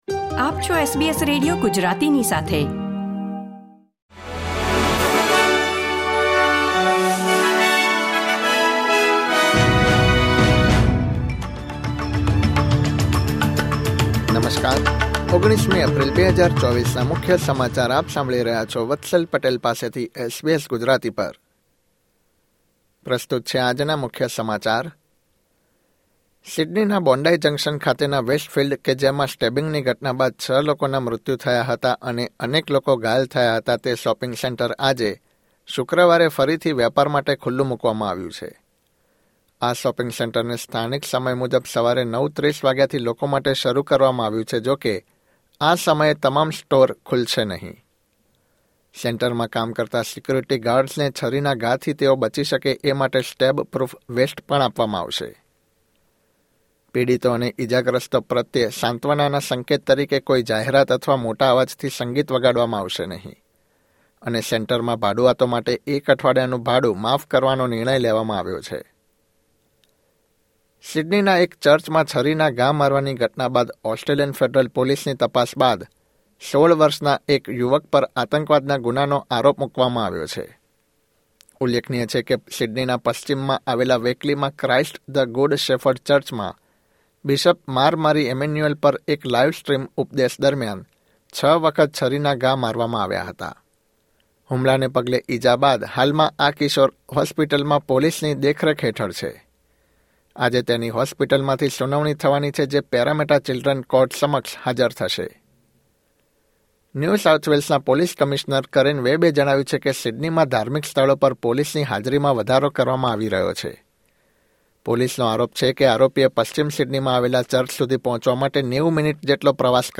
SBS Gujarati News Bulletin 19 April 2024